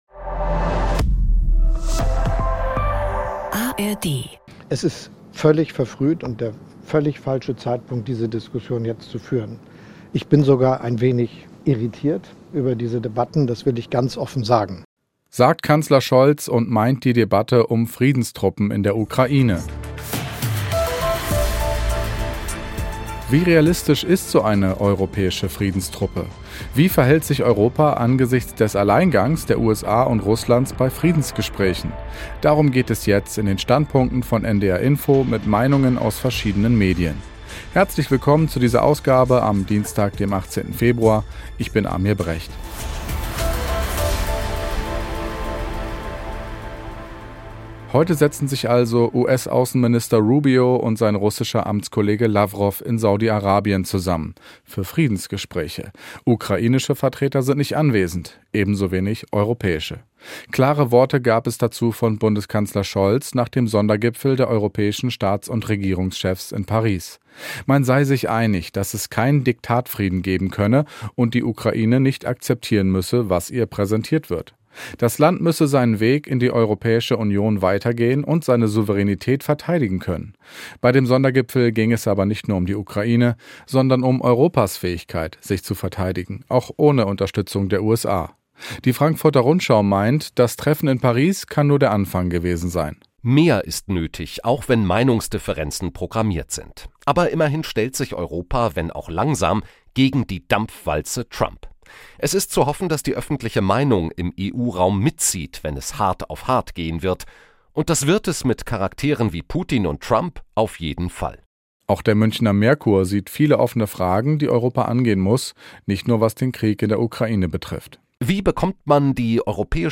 Außerdem gibt es Kommentare aus Podcasts, Newslettern, Radio- und TV-Sendungen wie beispielsweise den Tagesthemen.